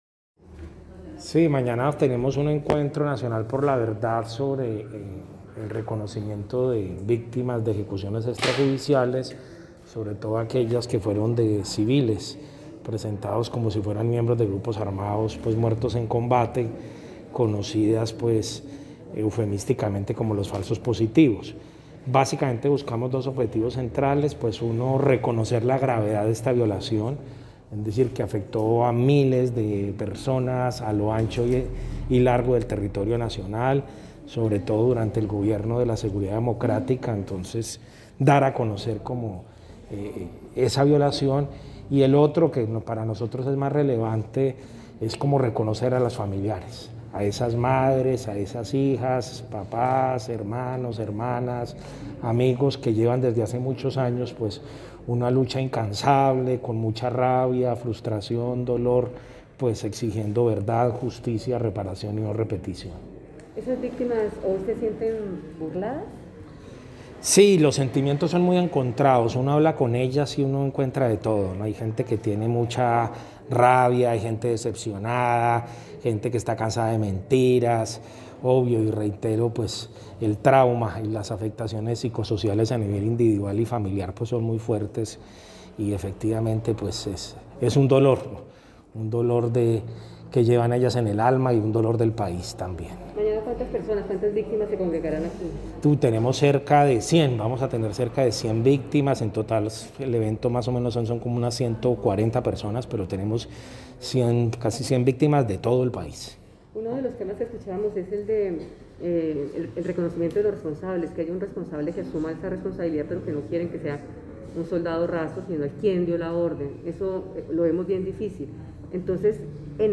Se adelanta a  esta hora el acto de reconocimiento por parte de la Comisión de la Verdad a las Víctimas de ejecuciones extrajudiciales, en la Casa La Merced de Cali.
El Comisionado de la Verdad, Alejandro Valencia, dijo al respecto: